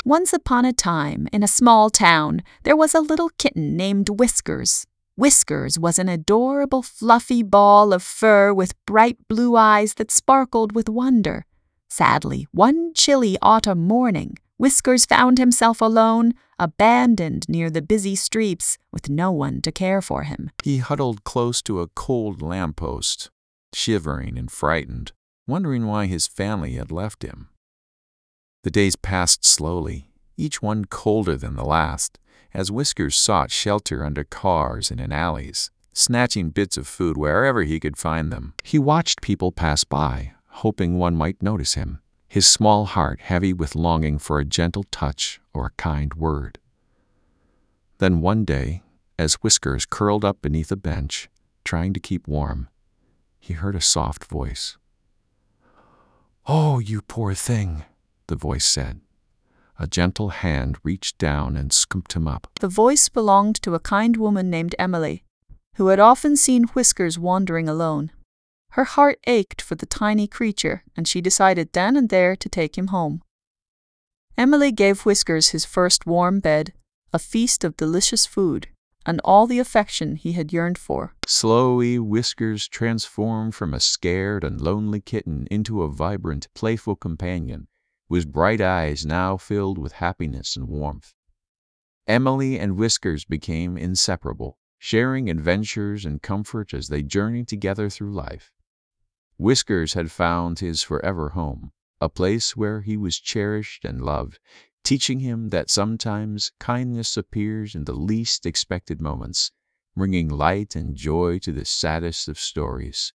story0_CopyMachine_1.wav